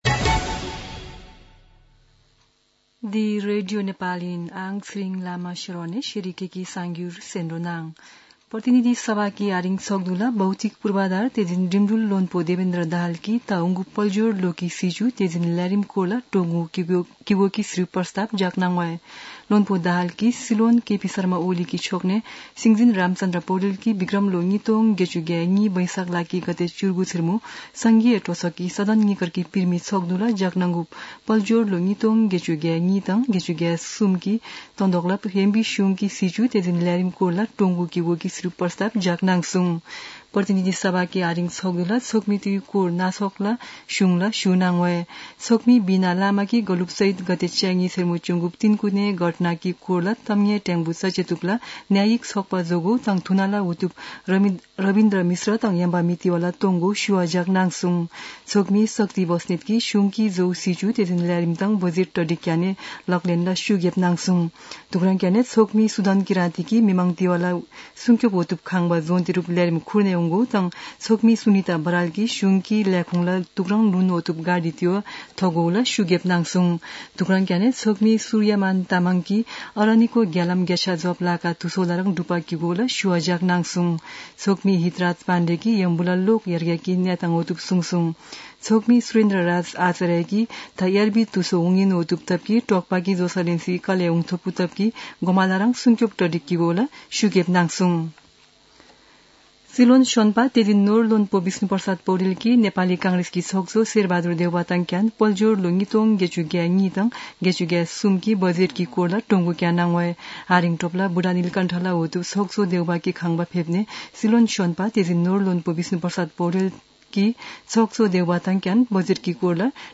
शेर्पा भाषाको समाचार : २२ वैशाख , २०८२
Sherpa-News-22.mp3